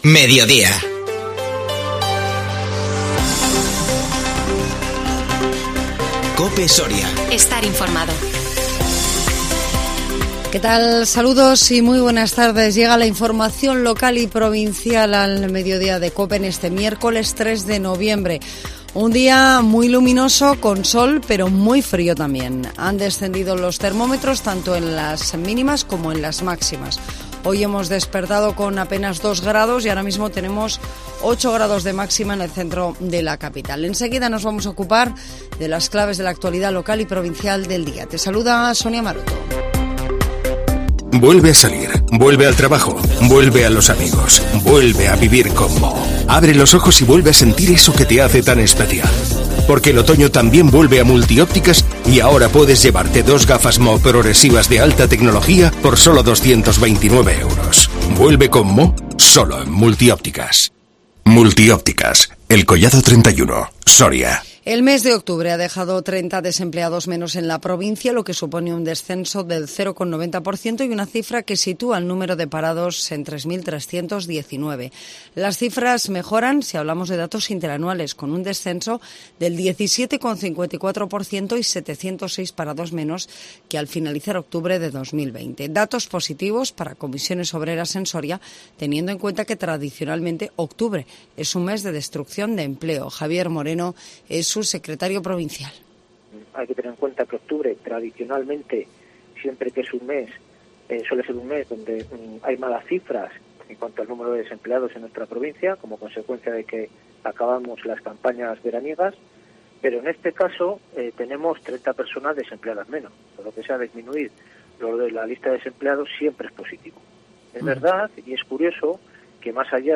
INFORMATIVO MEDIODÍA 3 NOVIEMBRE 2021